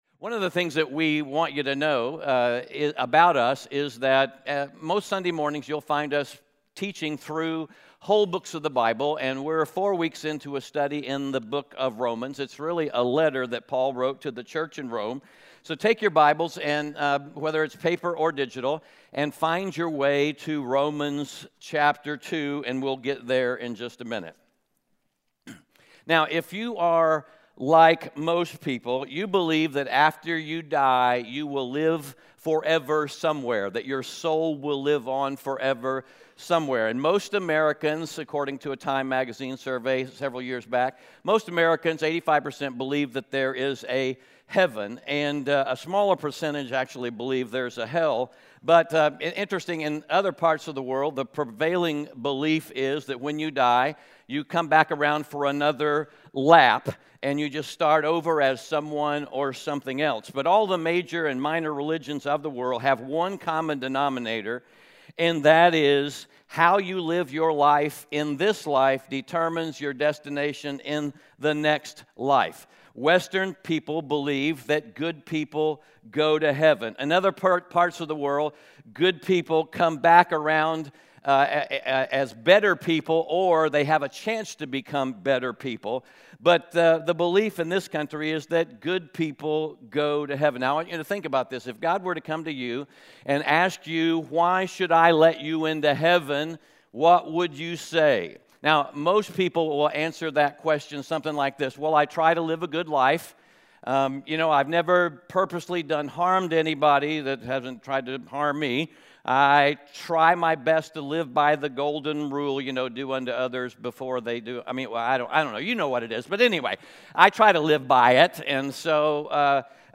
Romans 2:1-16 Audio Sermon Notes (PDF) Ask a Question *We are a church located in Greenville, South Carolina.